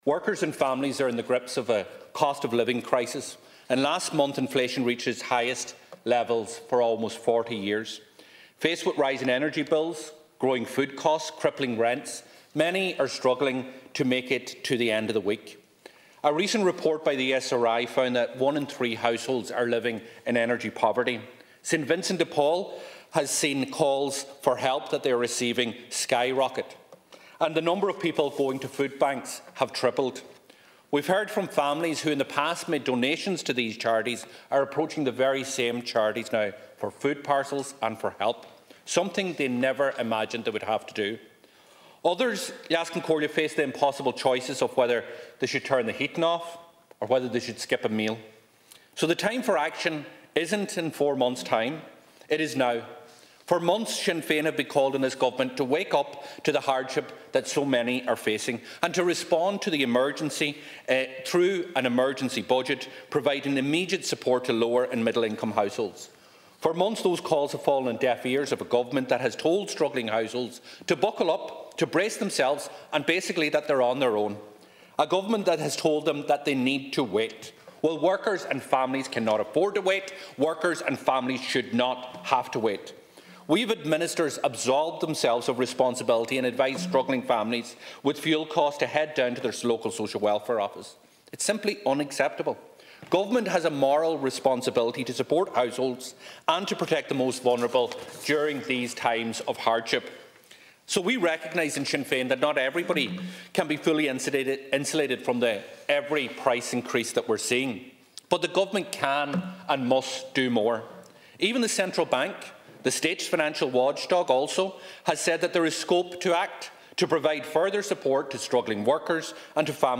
Sinn Fein’s Finance Spokesperson, Donegal Deputy Pearse Doherty called for an urgent intervention in the cost-of-living crisis.
He told the Dail last night that the Government needs to step up to the mark: